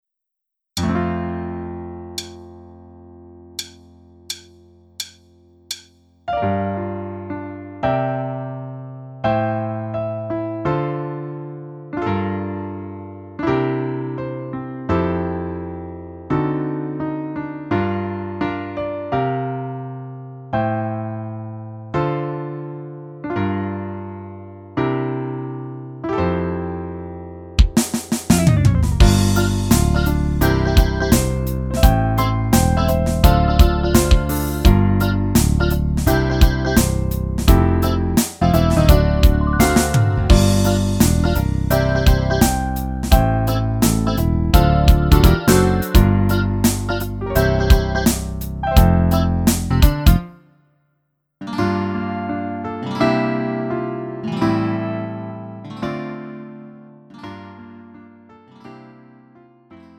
음정 원키 3:19
장르 가요 구분